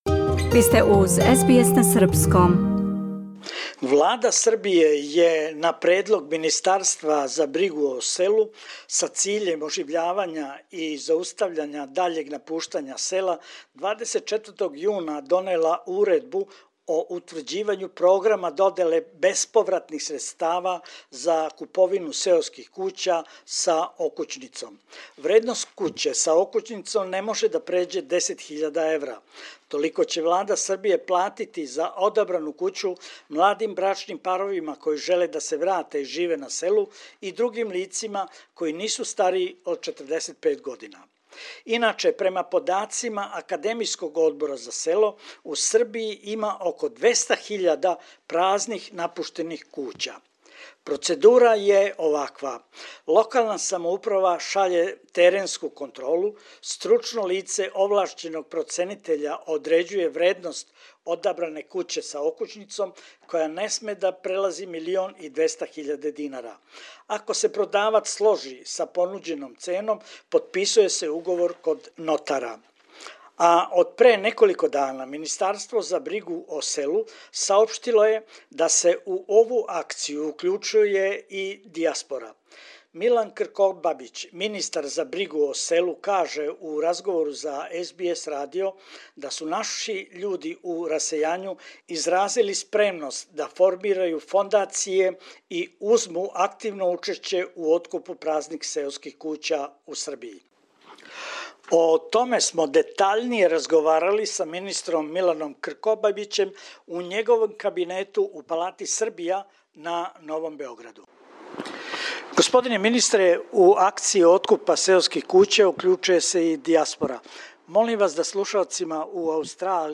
у његовом кабинету у Палати Србија на Новом Београду